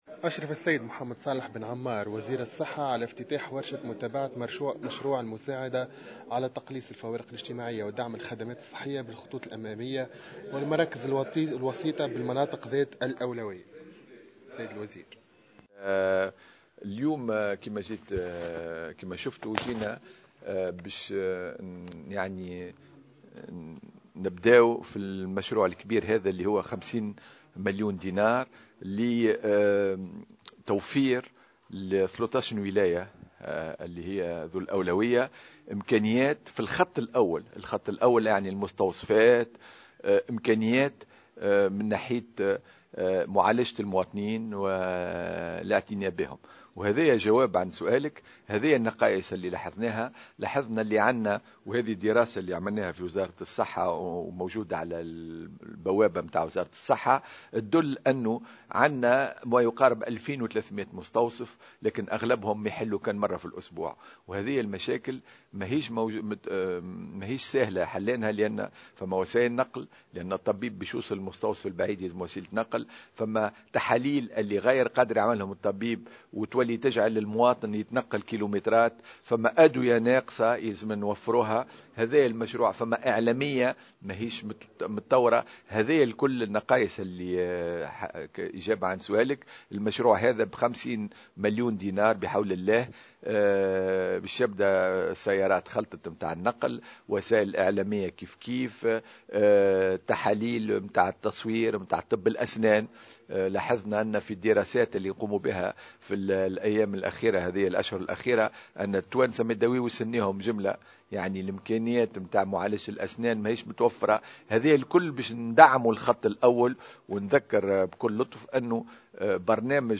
Le ministre de la santé publique, Mohamed Salah Ben Ammar, a déclaré ce jeudi 11 décembre 2014 sur les ondes de Jawhara FM, que le gouvernement a consacré 50 millions de dinars pour la construction de nouveaux dispensaires dans plusieurs régions de la Tunisie.